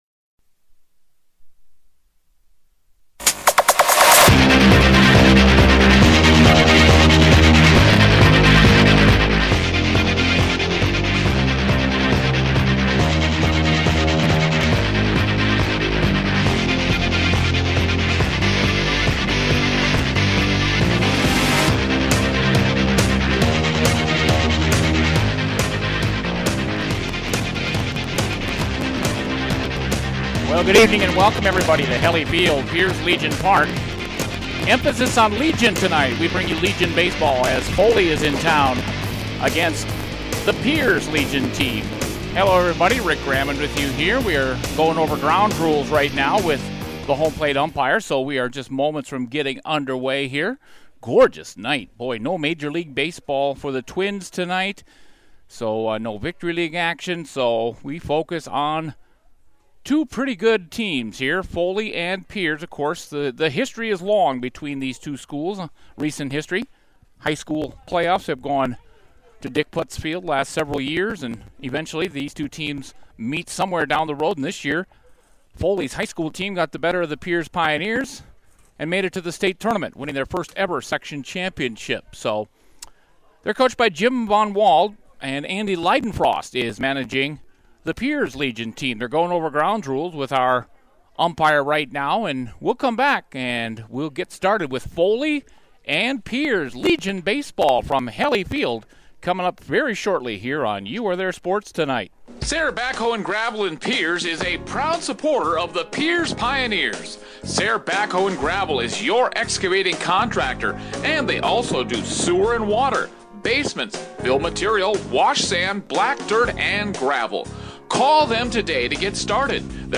Pierz hammered Foley 21-1 in American Legion Baseball from Hellie Field on Thursday. Pierz scored 8 times in the 1st, 9 times in the 3rd and 4 in the 4th.